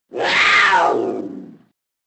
Пума грозно рыкнула